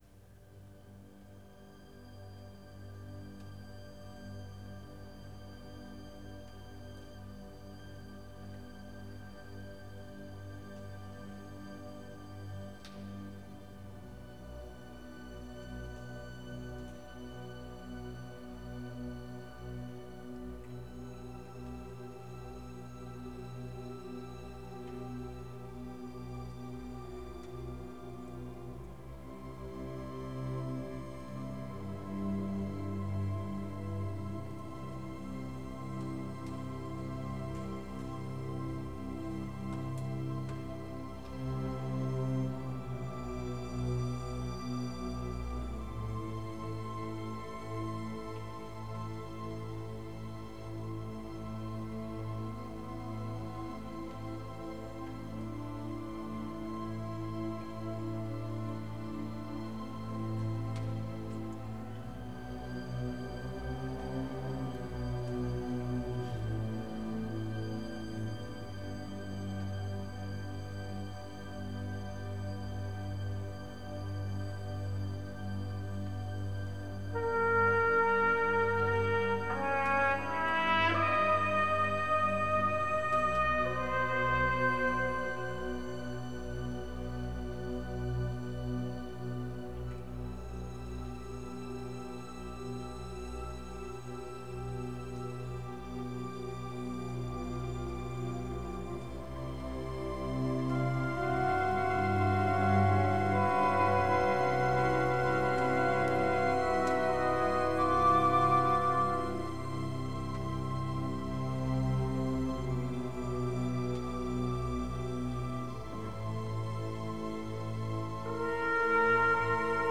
Música clásica
Orquesta